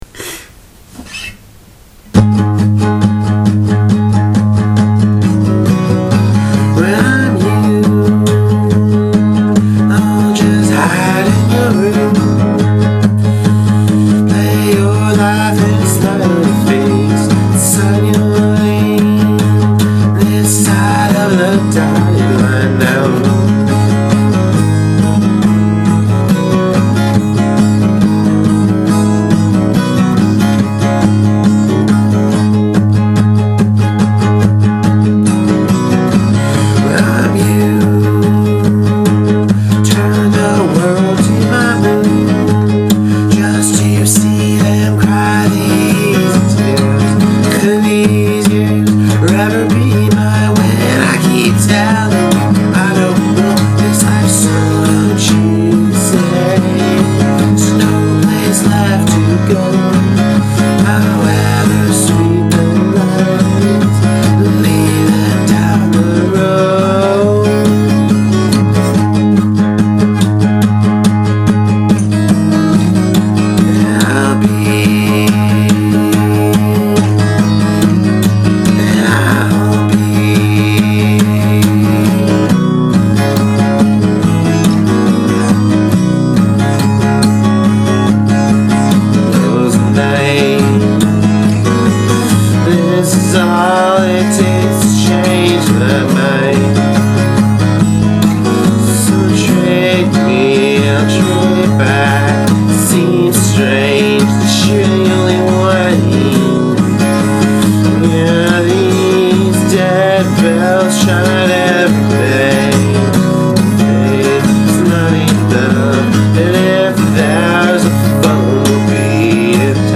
Very, very raw.